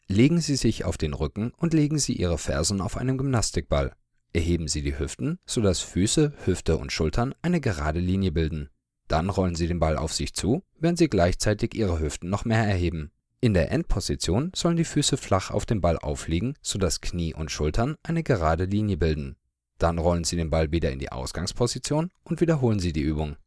German native speaker with more than 7 years of professional Voice Over experience for all your audio needs.
Sprechprobe: Industrie (Muttersprache):